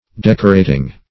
Decorating (d[e^]k"[-o]*r[=a]`t[i^]ng).]